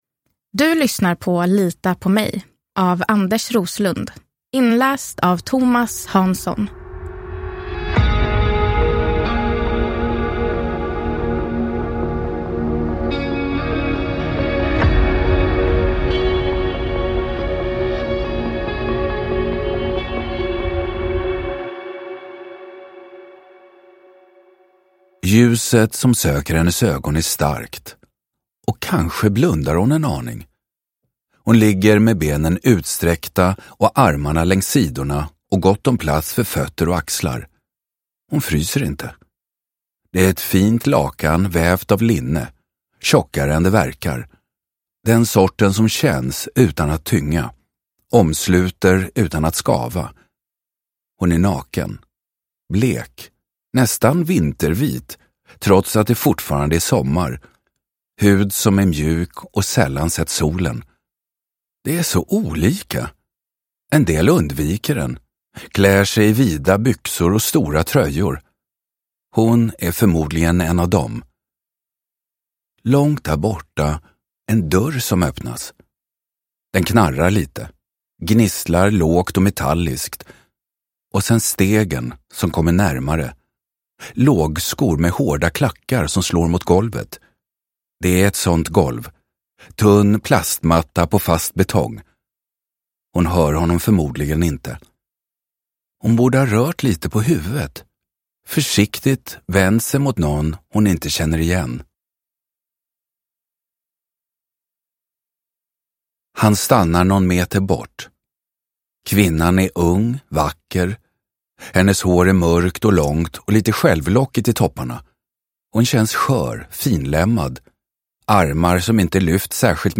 Uppläsare: Thomas Hanzon
Ljudbok